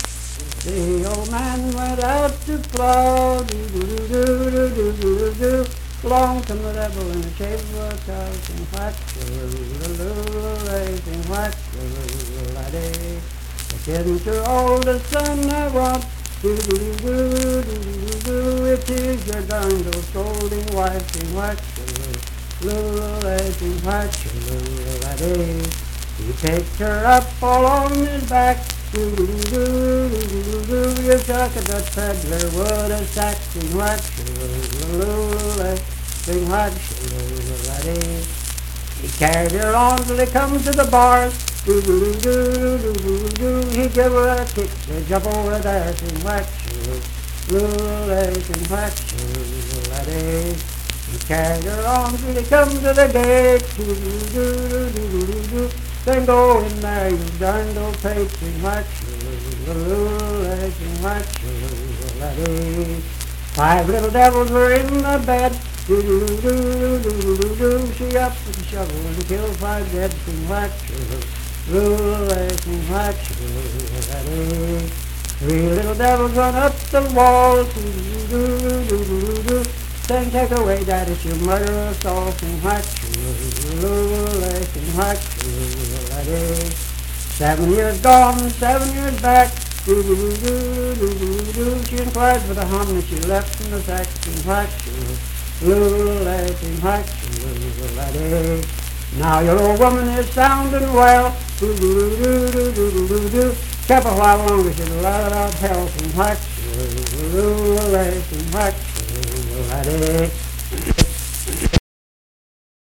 Unaccompanied vocal music
Voice (sung)
Harrison County (W. Va.)